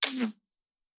На этой странице собраны реалистичные звуки выстрелов из рогатки разными снарядами: от камней до металлических шариков.
Снаряд пронесся возле уха